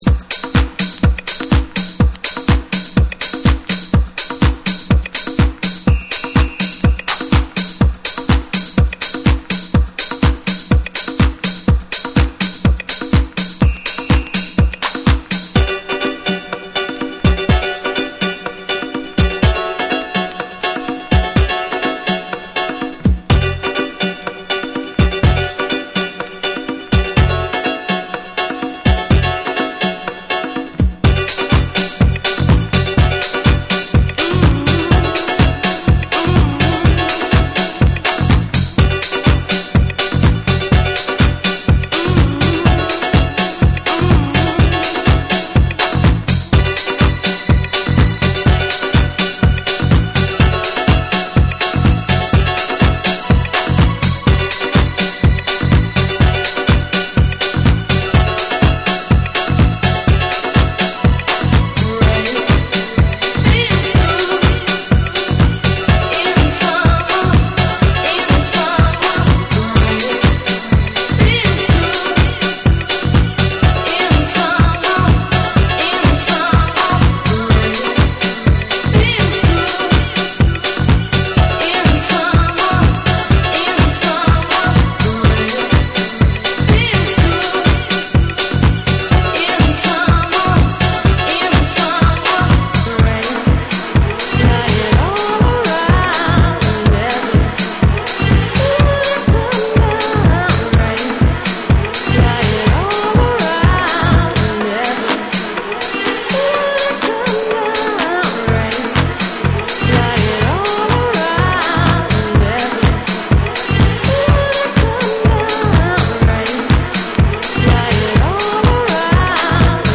ジャジーで柔らかいアプローチが光る
ドリーミーで幻想的な空気感を湛えた
TECHNO & HOUSE